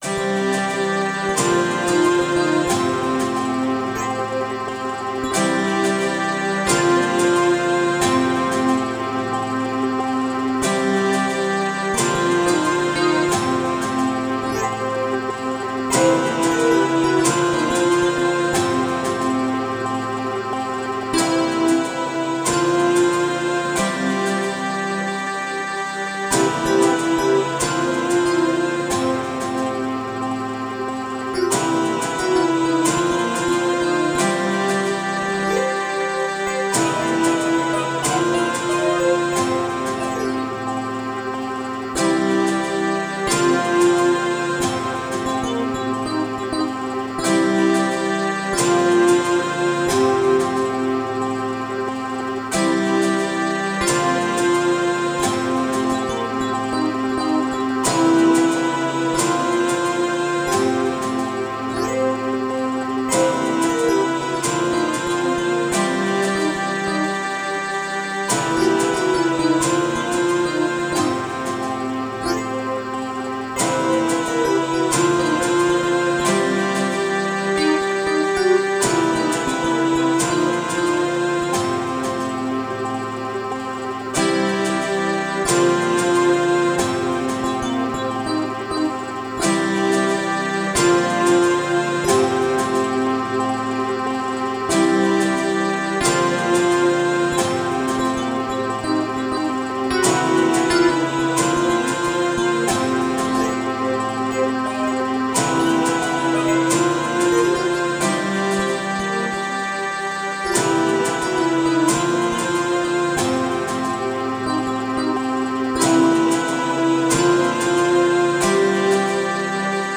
Fröhliche, positive Stimmung.
Tempo: 46 bpm / Datum: 07.11.2017